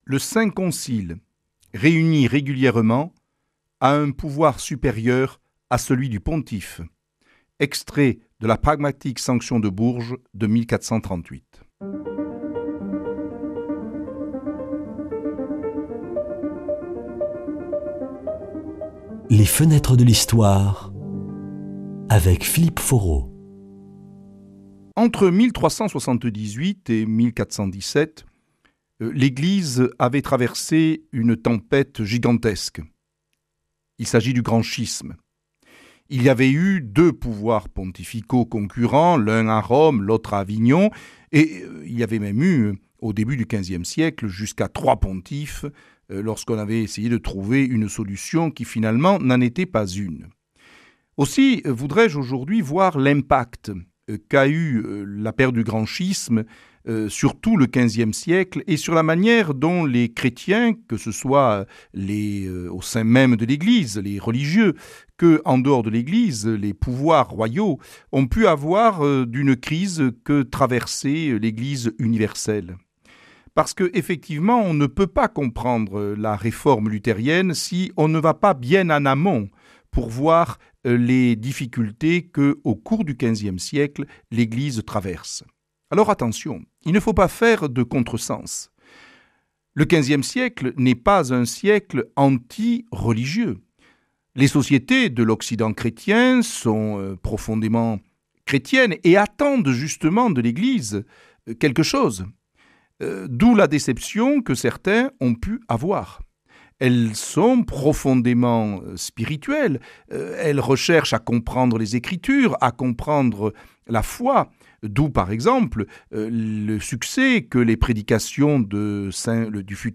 [ Rediffusion ] Après la crise du Grand Schisme, l’Eglise catholique est contestée de plusieurs manières.